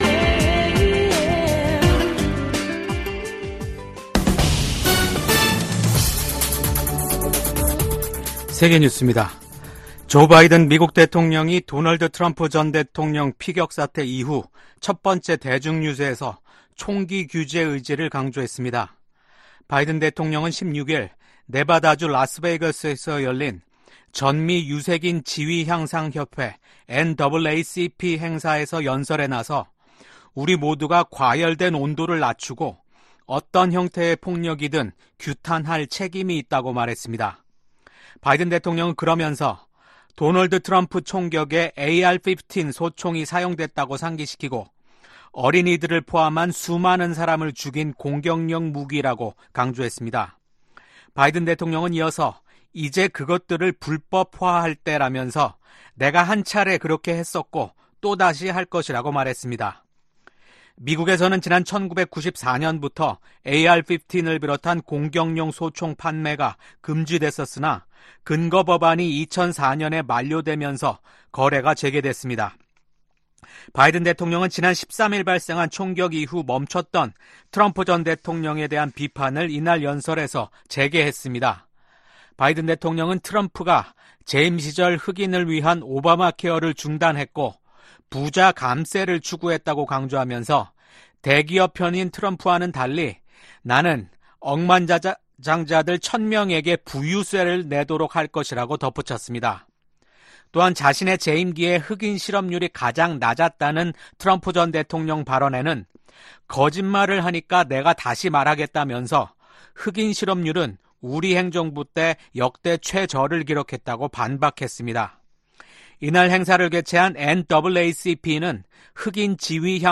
VOA 한국어 아침 뉴스 프로그램 '워싱턴 뉴스 광장' 2024년 7월 18일 방송입니다. 미 국방부의 고위 관리가 VOA와의 단독 인터뷰에서 미한 양국은 핵을 기반으로 한 동맹이라고 밝혔습니다. 북한의 엘리트 계층인 외교관들의 한국 망명이 이어지고 있습니다. 북한에서 강제노동이 광범위하게 제도화돼 있으며 일부는 반인도 범죄인 노예화에 해당할 수 있다고 유엔이 지적했습니다.